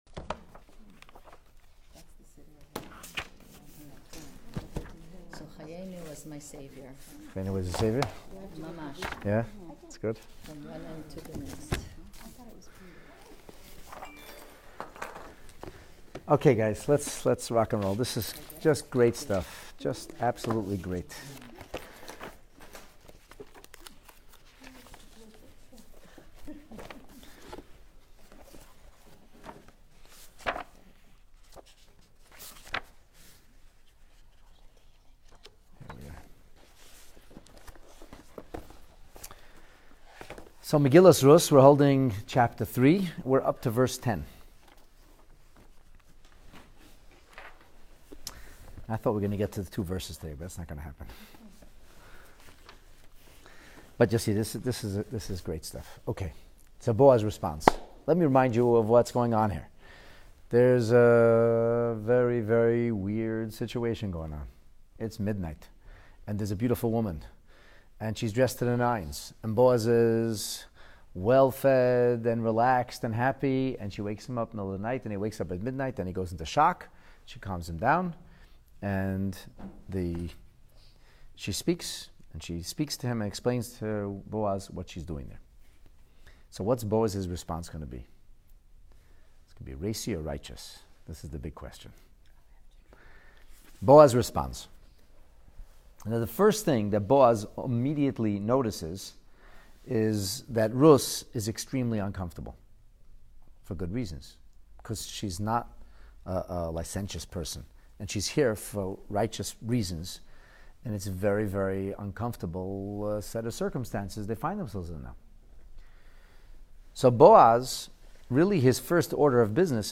Megillat Ruth in Depth Part 26 Chapter 3, Lesson 8 Boaz’s Response: Righteous or Racy?